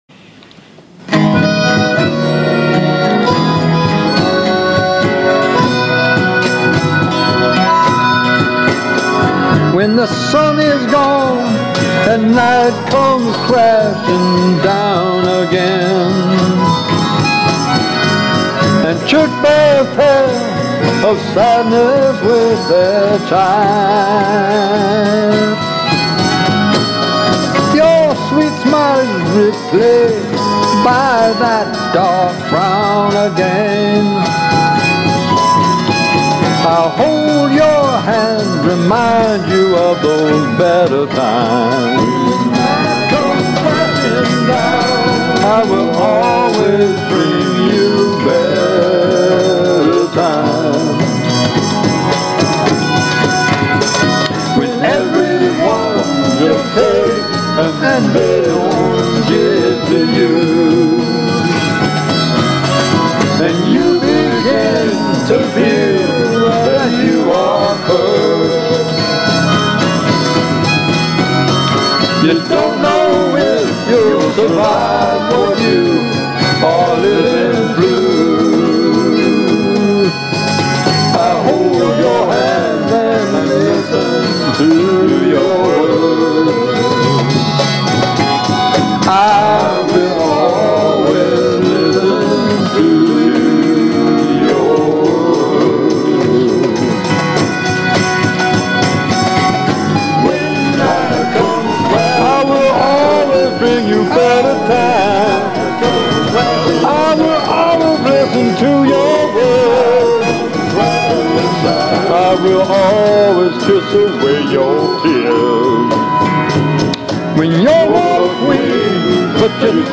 this poem put to music